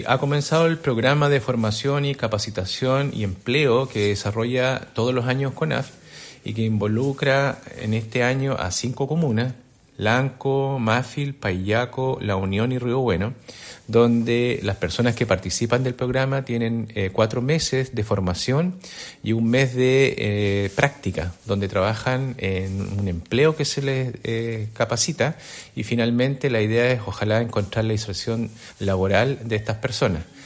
Arnoldo Shibar, director regional de CONAF Los Ríos.